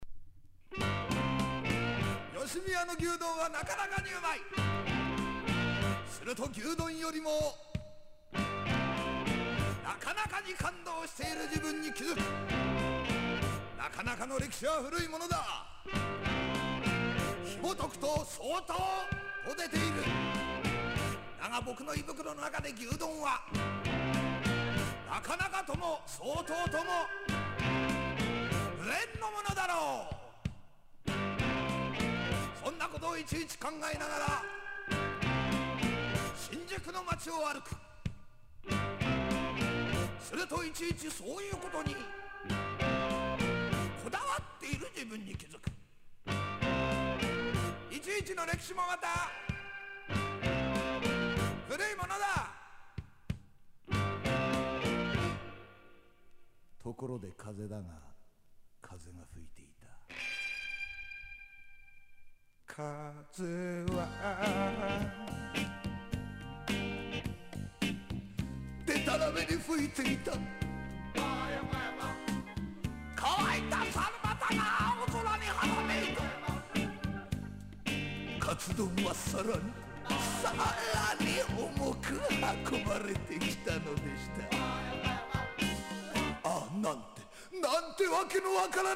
60-80’S ROCK# SSW / FOLK